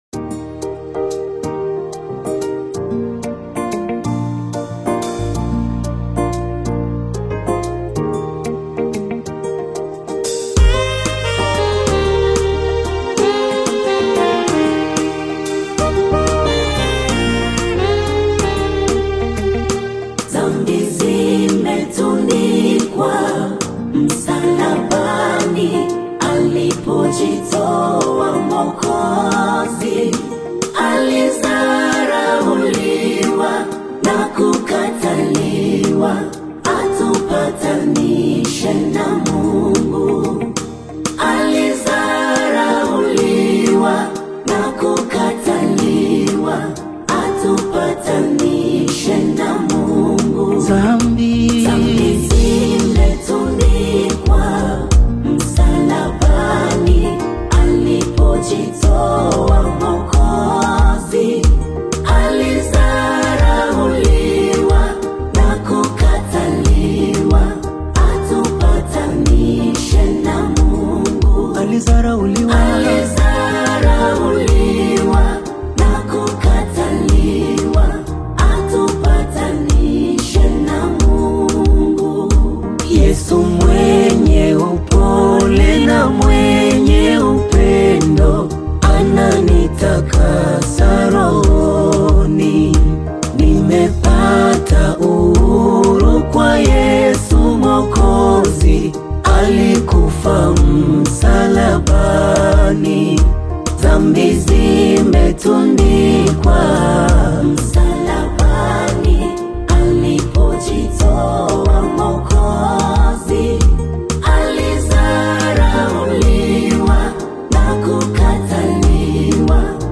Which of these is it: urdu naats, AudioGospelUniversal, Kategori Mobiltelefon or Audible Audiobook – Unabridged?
AudioGospelUniversal